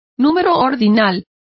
Complete with pronunciation of the translation of ordinal.